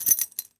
foley_keys_belt_metal_jingle_13.wav